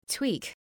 Προφορά
{twi:k}